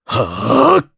Dimael-Vox_Casting3_jp.wav